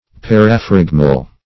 -- Par`a*phrag"mal , a. [1913 Webster]